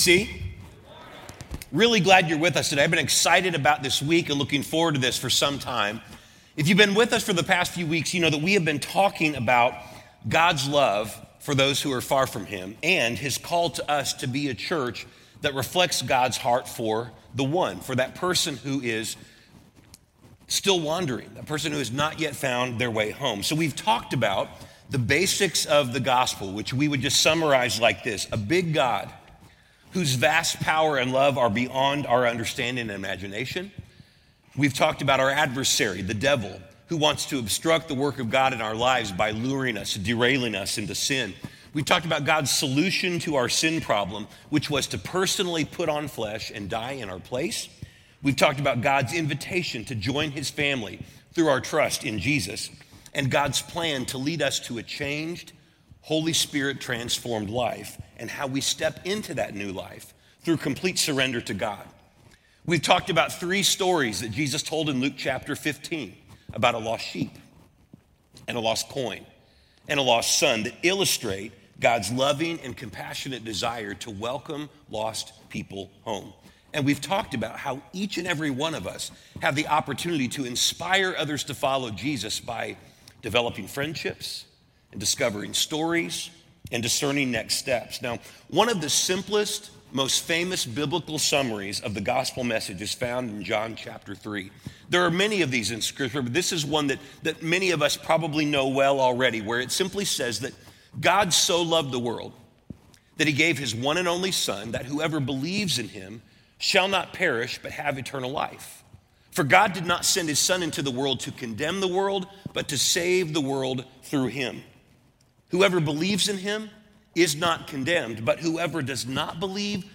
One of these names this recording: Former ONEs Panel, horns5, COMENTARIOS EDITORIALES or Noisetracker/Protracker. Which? Former ONEs Panel